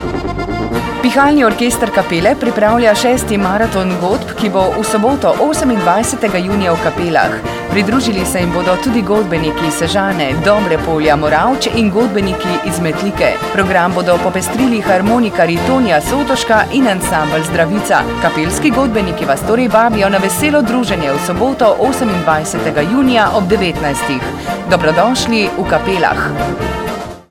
*** 2008 - 6. maraton orkestrov pod šotorom v Kapelah
...reklama za prireditev v živo